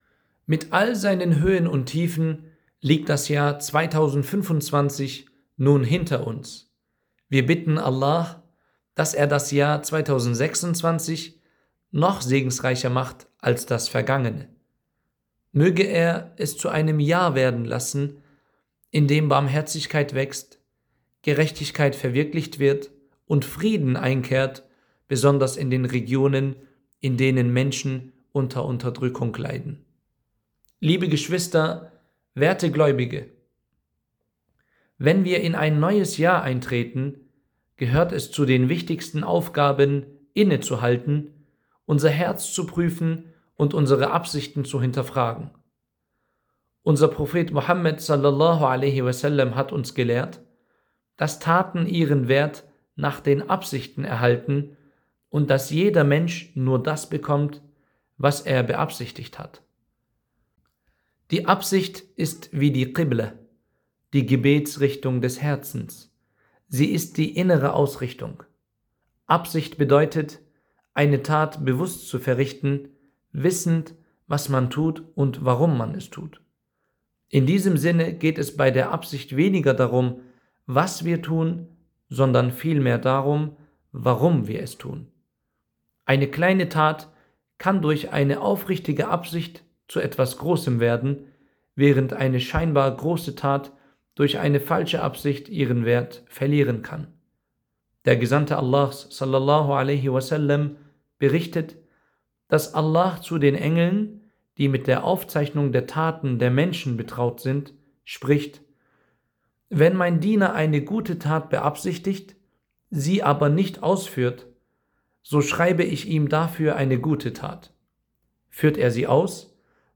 Freitagspredigt